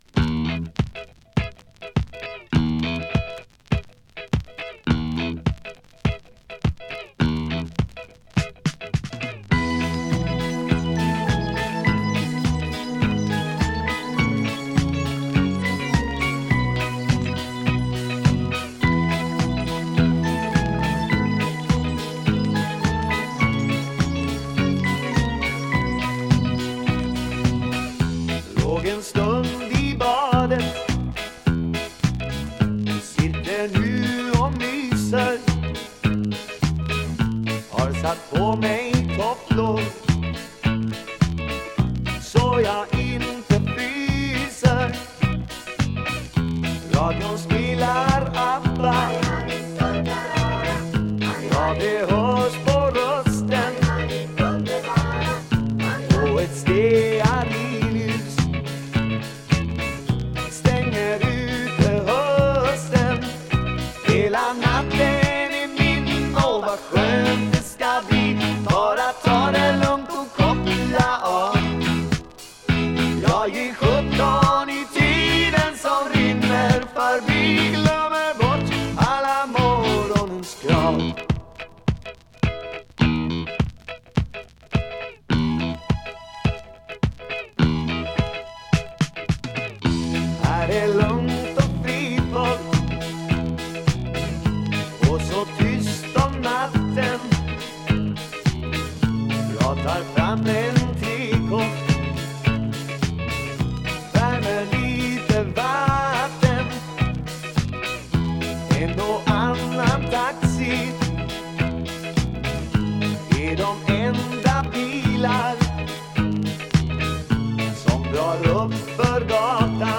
Vocals, Piano, Fender Rhodes 88,
Bass
Vocals, Drums, Percussion
Vocals, Guitars, Mandolin